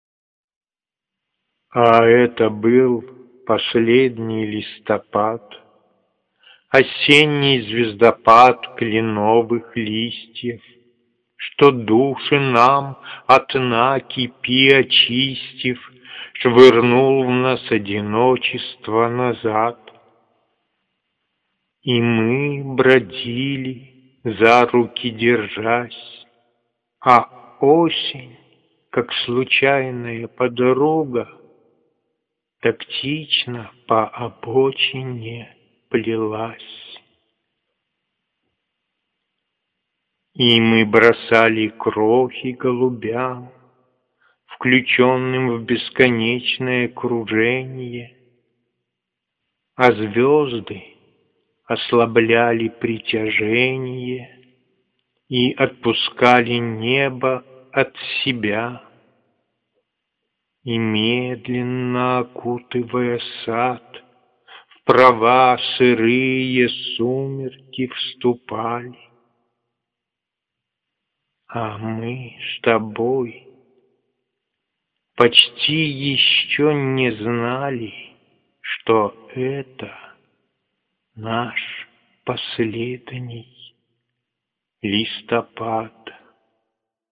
звучащие стихи